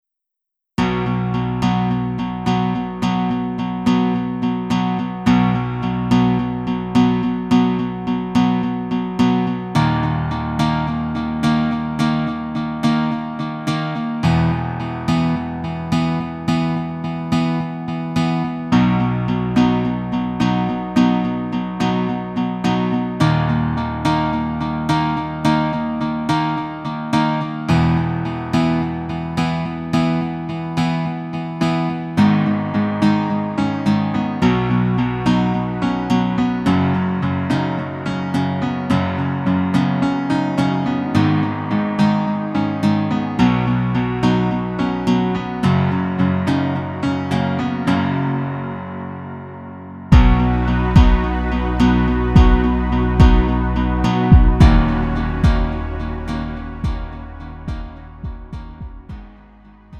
음정 원키 3:23
장르 구분 Lite MR